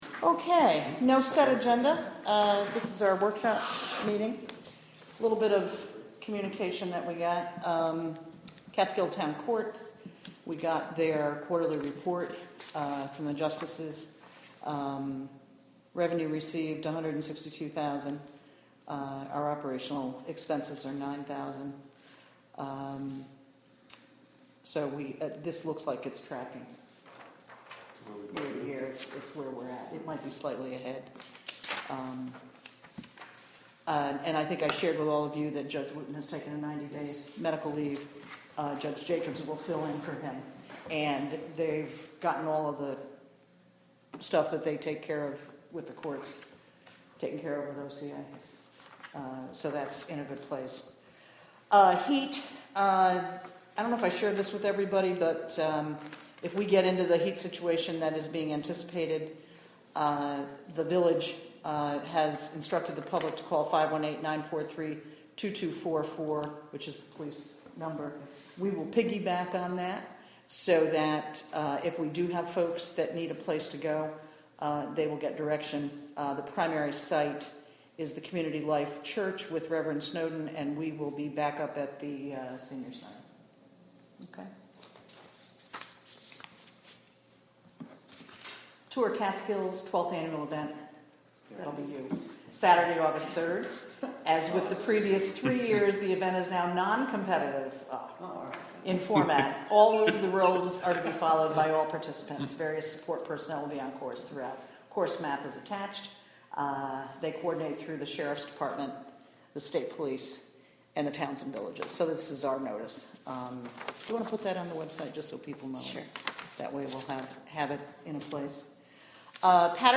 Recorded from a live webstream created by the Town of Catskill through the Wave Farm Radio app.